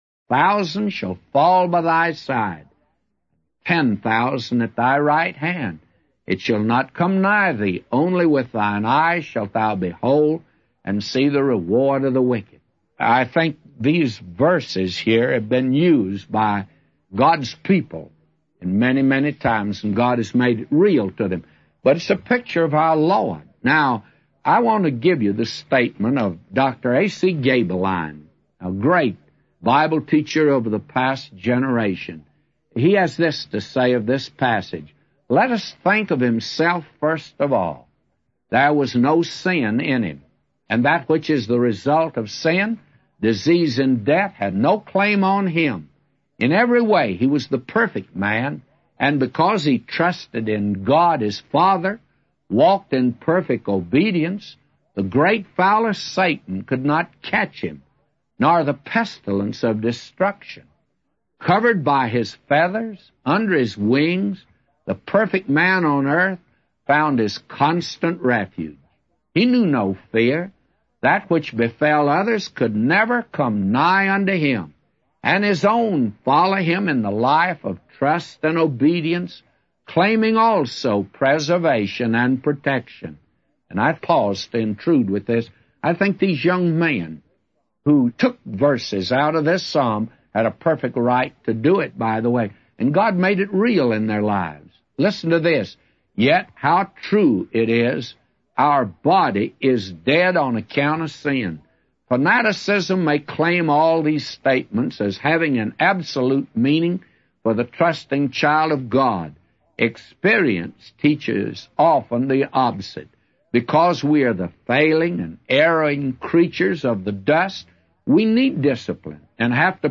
A Commentary By J Vernon MCgee For Psalms 91:7-999